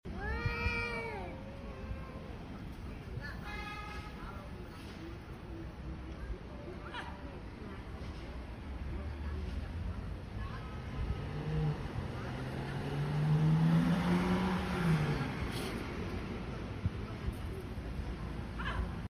Dogs That See Cats Quarreling Sound Effects Free Download